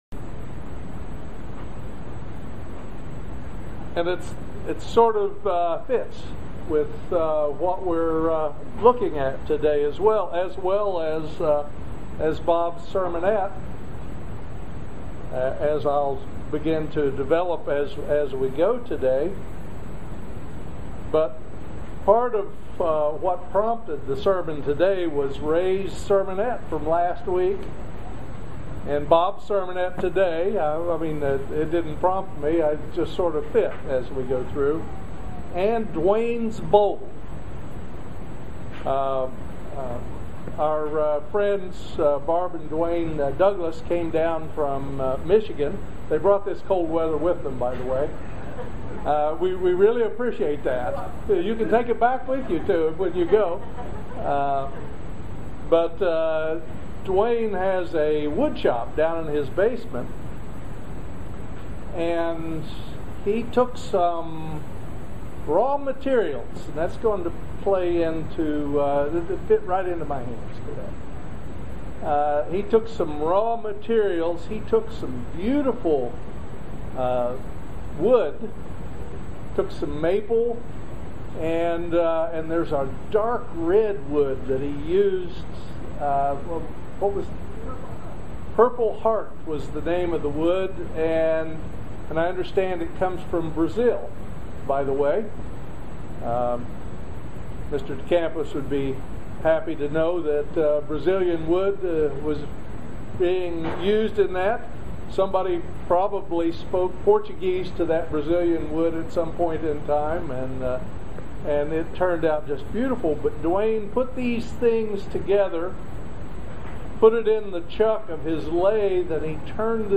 Join us for this eye opening video sermon . Are we doing the things God wants us to do? Or are we just going through the motions as the Pharisees did?
Given in Lexington, KY